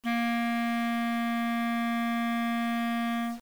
This distortion corresponds to a buzz-like sound that "follows" the pitch of the quantized sound.
clarinet at 8 bits , clarinet at 8 bits with dithering and
clarinetto8.wav